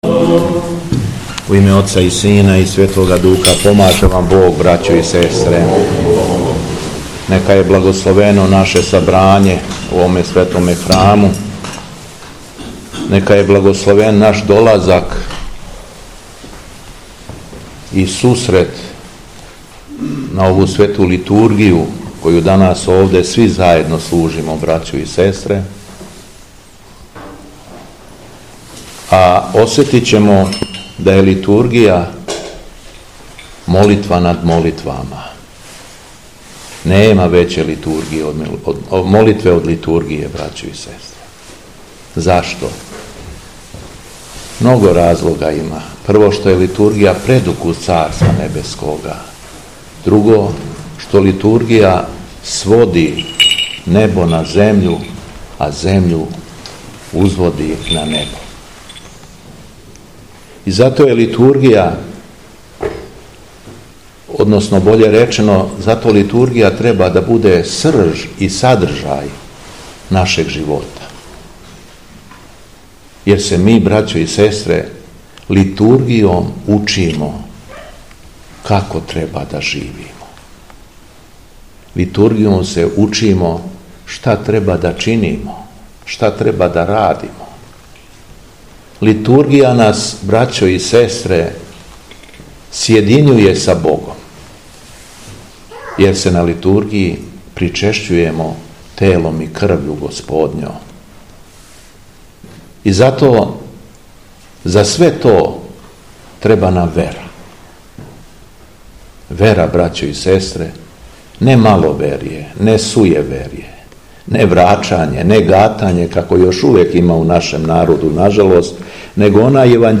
ЕВХАРИСТИЈСКО САБРАЊЕ У ХРАМУ СВЕТОГ ПРОРОКА ИЛИЈЕ У ГРБИЦАМА
Беседа Његовог Високопреосвештенства Митрополита шумадијског г. Јована